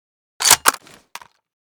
k98_close.ogg.bak